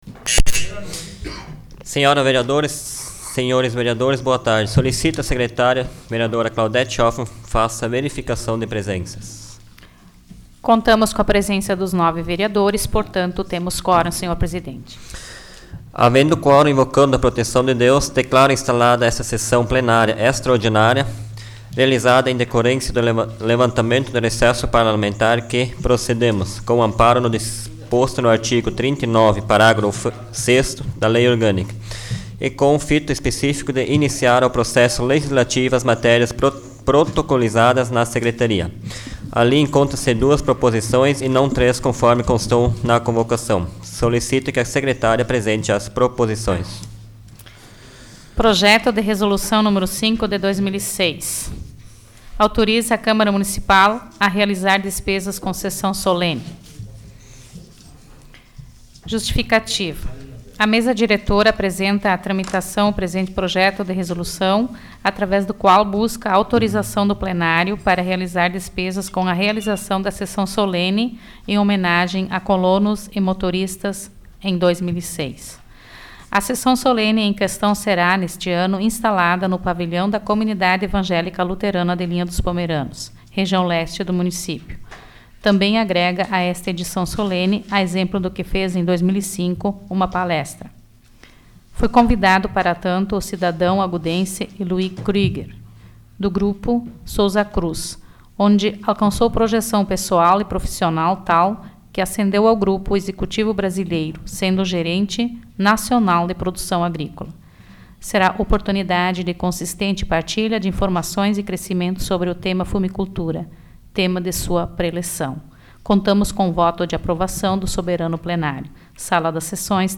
Áudio da 22ª Sessão Plenária Extraordinária da 12ª Legislatura, de 18 de julho de 2006